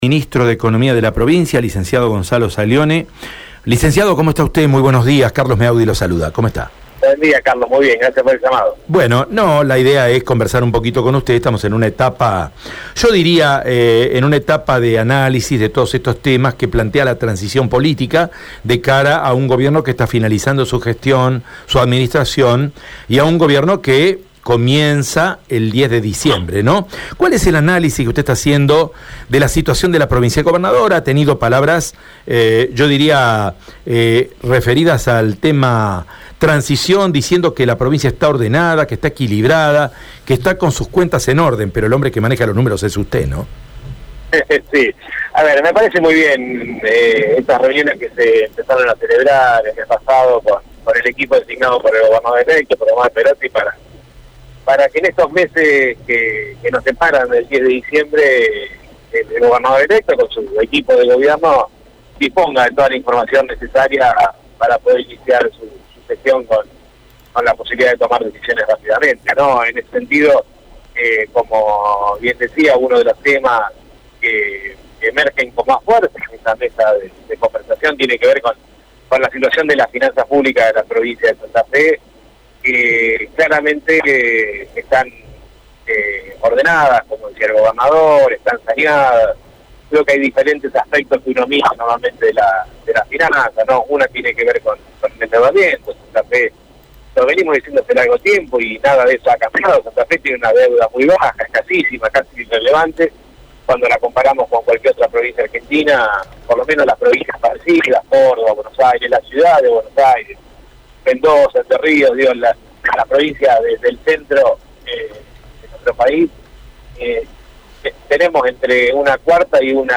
El ministro de Economía de la provincia dialogó con Radio EME sobre la transición, las cuentas provinciales y las deudas que el Estado Nacional mantiene
gonzalo-saglione-ministro-de-economia.mp3